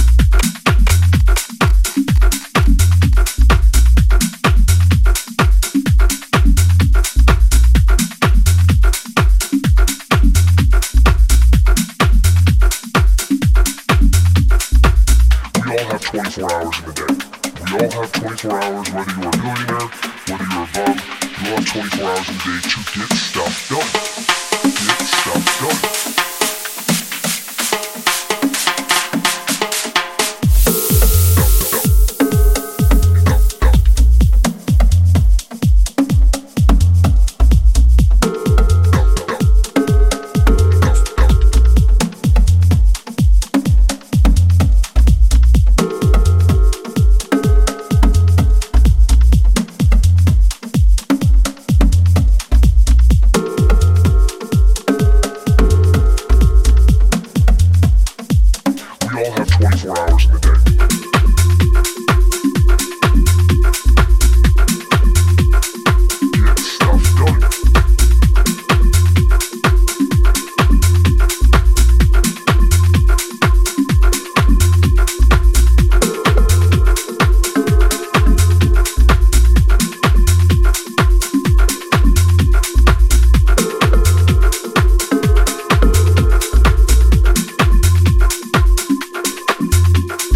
タイトなキックとダビーなベースラインの足回りが使い勝手抜群のミニマル・ハウスのA面もナイスですが